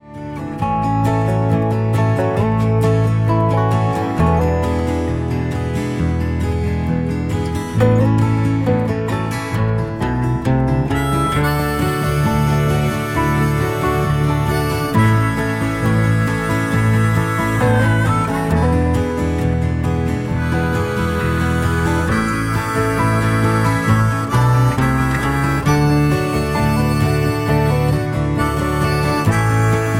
MPEG 1 Layer 3 (Stereo)
Backing track Karaoke
Country, 1970s